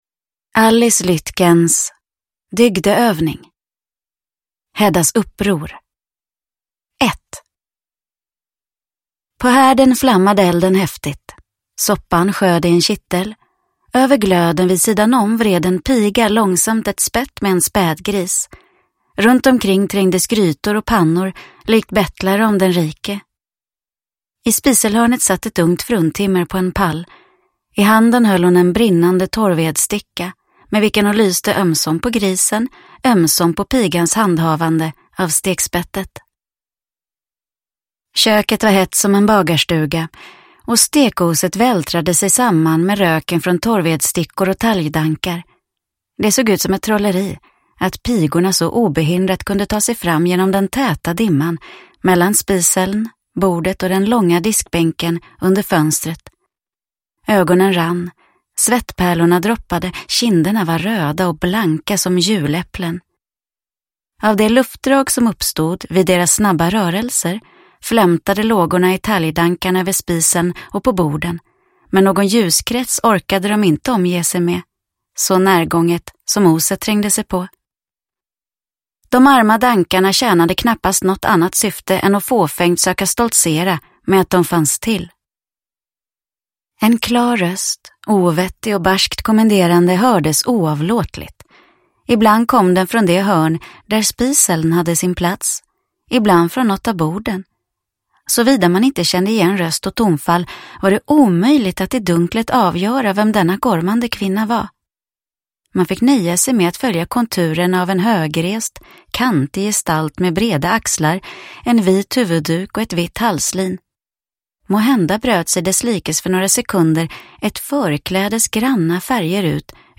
Dygdeövning – Ljudbok – Laddas ner